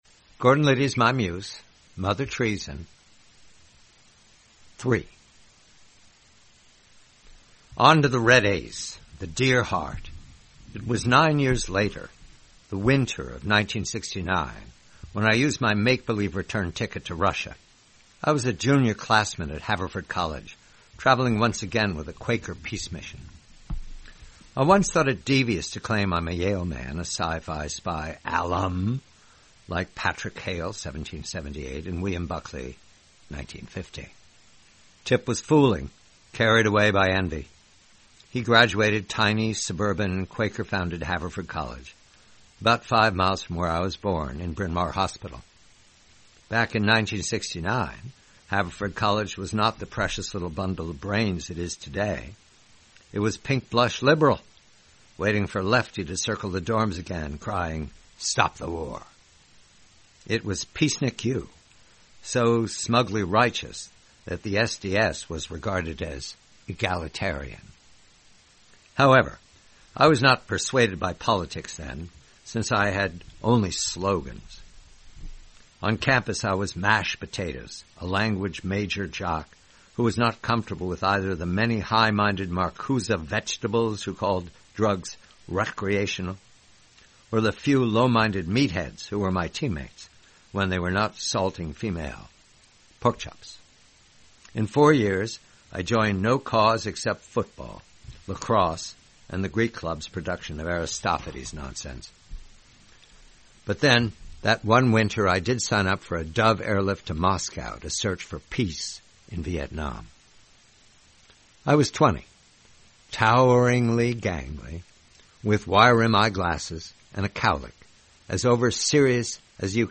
Read by John Batchelor.